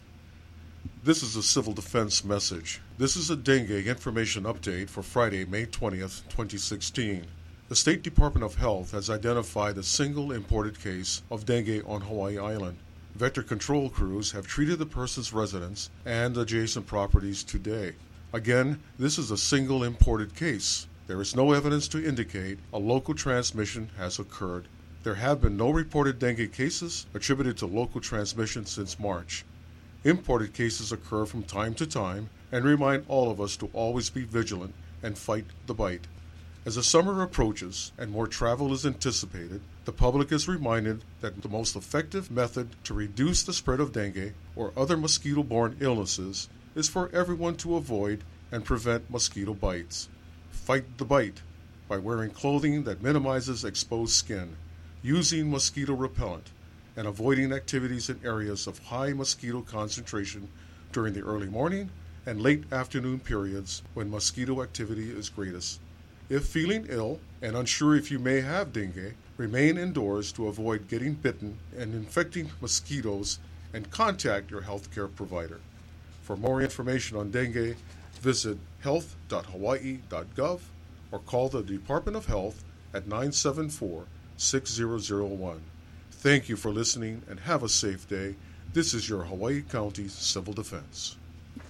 HAWAII ISLAND – The new director of Hawaii County Civil Defense has delivered his first audio alert message, and its about dengue fever.